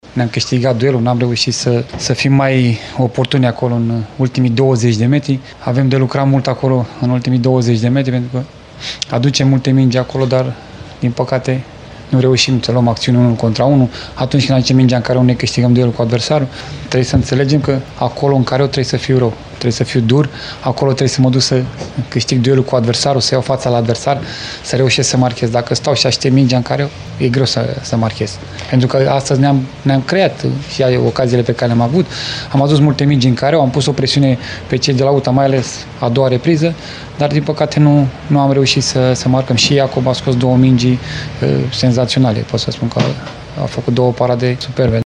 În schimb, antrenorul ilfovenilor, Nicolae Dică, a reproșat propriilor jucători lipsa de agresivitate din ofensivă: